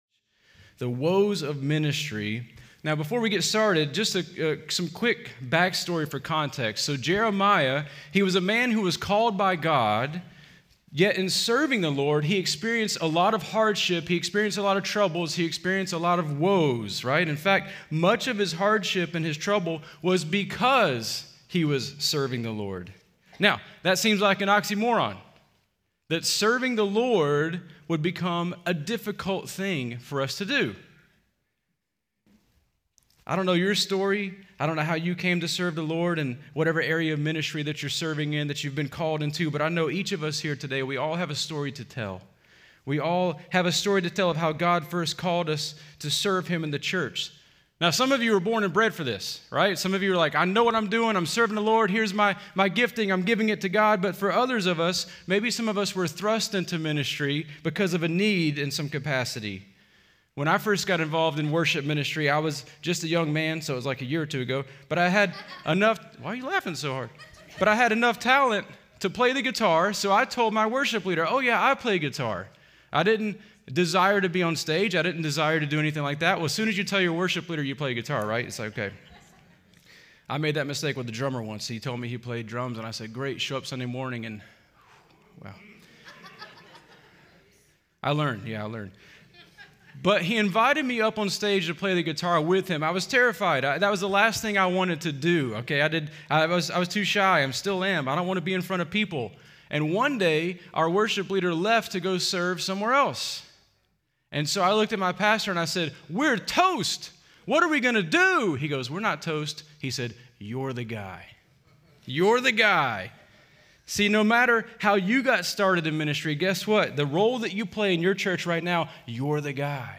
Home » Sermons » The Impact of One Worshipping Heart
Conference: Worship Conference